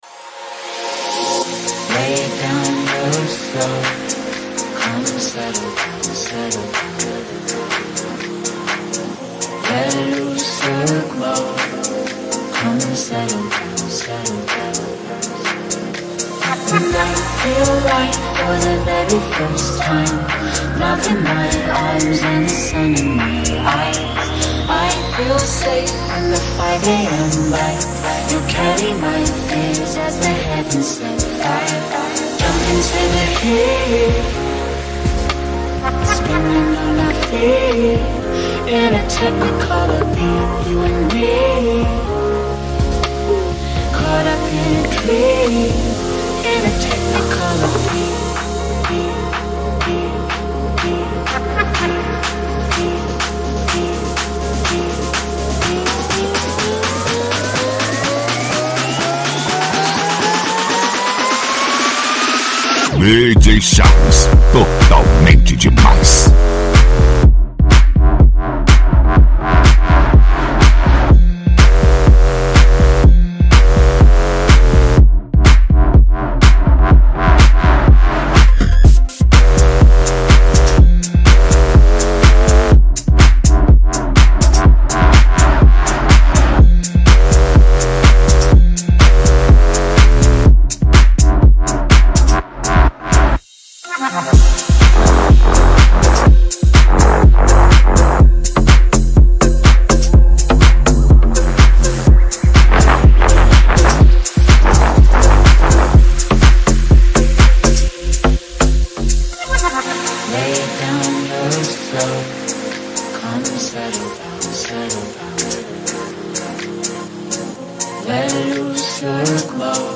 DEEP HOUSE.